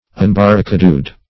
Search Result for " unbarricadoed" : The Collaborative International Dictionary of English v.0.48: Unbarricadoed \Un*bar`ri*ca"doed\, a. Not obstructed by barricades; open; as, unbarricadoed streets.
unbarricadoed.mp3